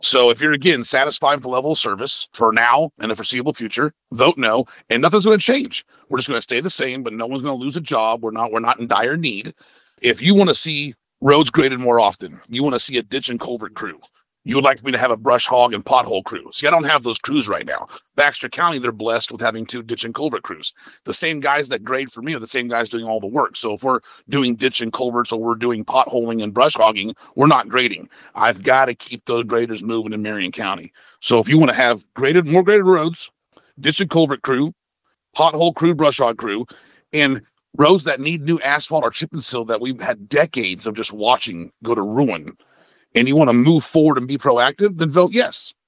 KTLO News spoke with Marion County Judge Jason Stumph who says currently the Sheriff’s department creates a shortfall of approximately $900,000 to $1.2 million annually for the county general fund. His hope is that with the passing of the tax it funds would be freed up to focus on roads.